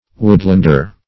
Woodlander \Wood"land*er\, n.